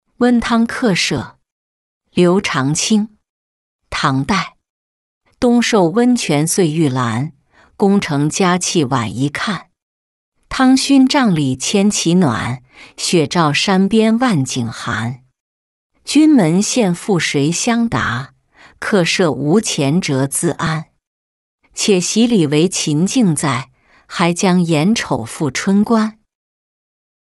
温汤客舍-音频朗读